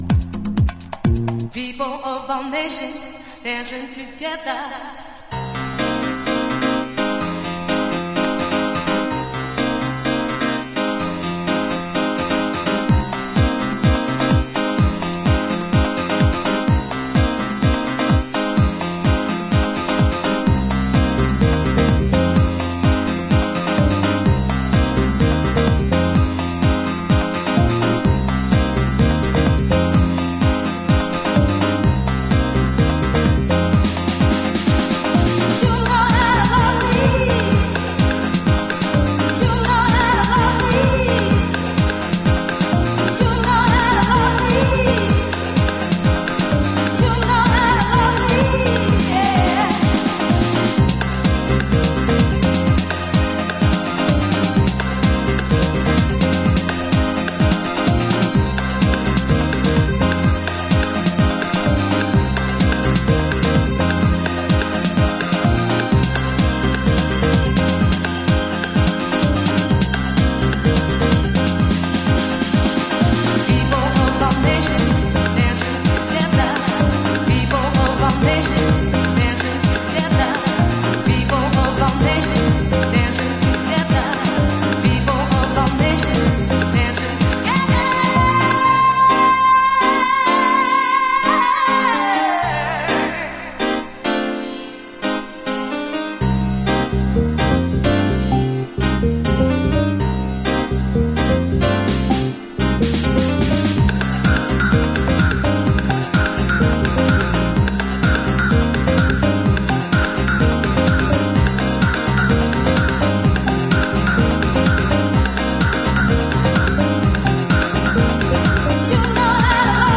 シカゴ伝統のパーカッシブジャックビート